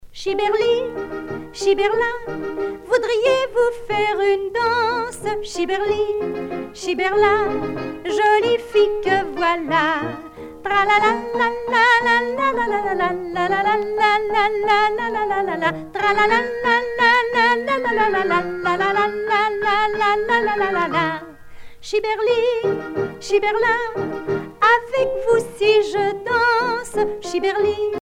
Enfantines - rondes et jeux
Pièce musicale éditée